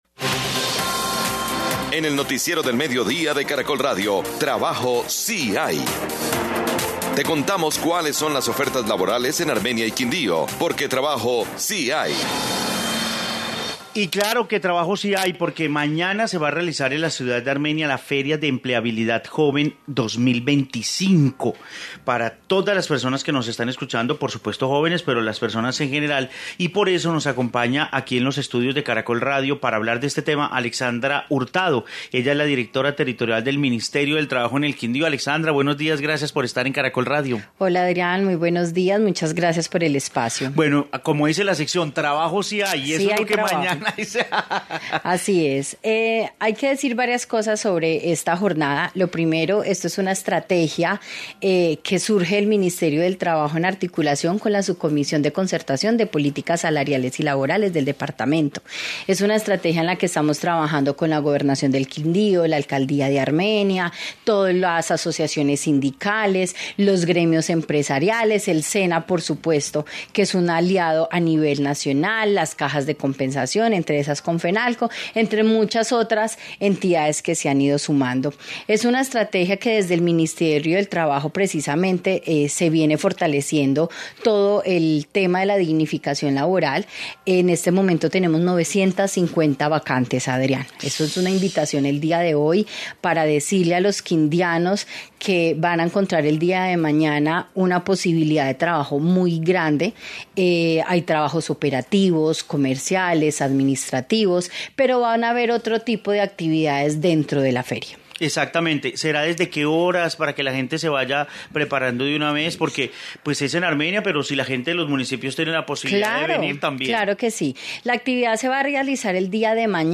Alexandra Hurtado, directora Ministerio de Trabajo, Quindío